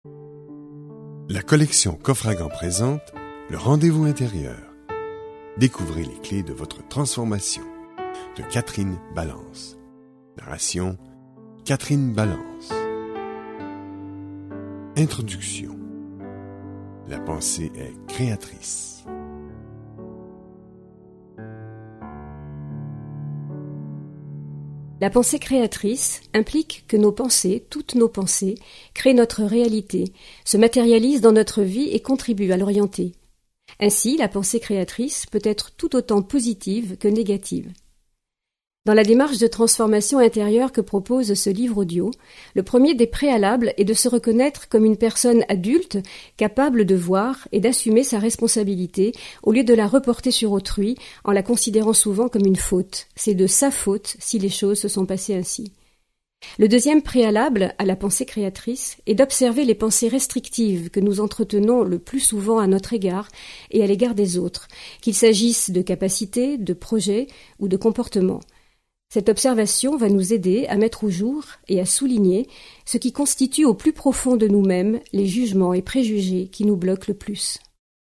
Extrait gratuit
En vous invitant à vous questionner sur la relation que vous entretenez avec vous-même, ce livre audio vous aidera à entreprendre votre démarche personnelle.